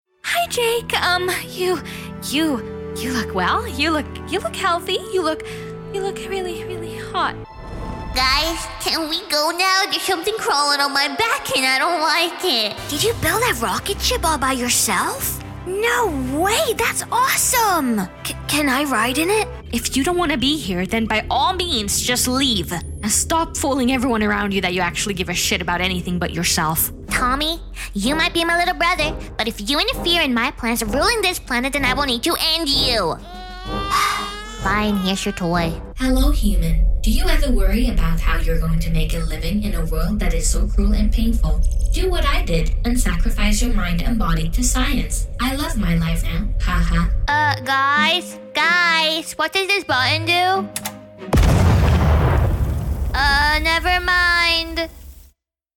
Demo
Young Adult, Adult
Has Own Studio
eastern european | natural
middle eastern | natural
standard us | character
swedish | natural
ANIMATION 🎬